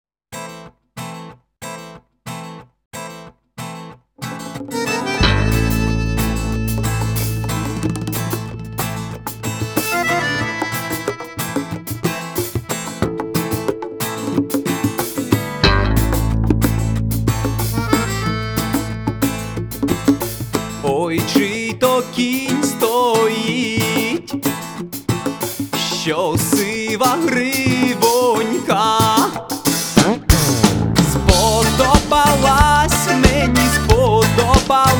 Жанр: Фолк-рок / Рок / Украинские